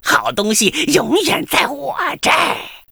文件 文件历史 文件用途 全域文件用途 Gbn_tk_02.ogg （Ogg Vorbis声音文件，长度2.9秒，105 kbps，文件大小：37 KB） 源地址:游戏语音 文件历史 点击某个日期/时间查看对应时刻的文件。